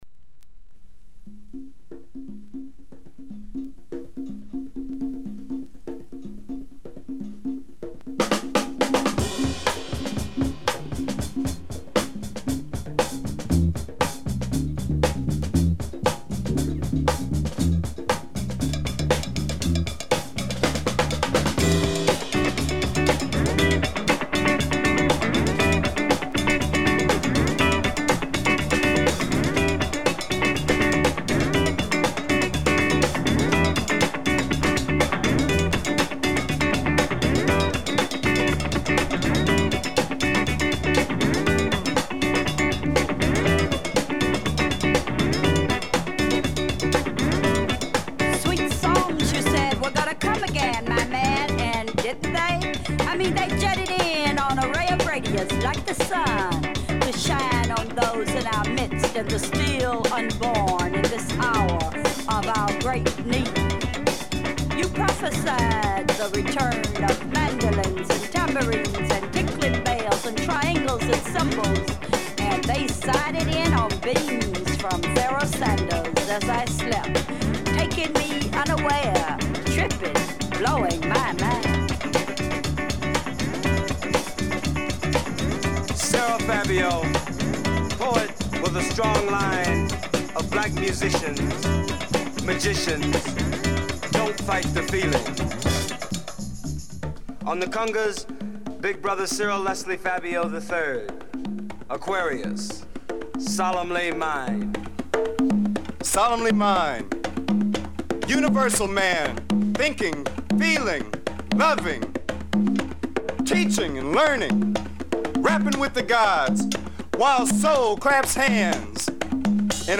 afro funk groove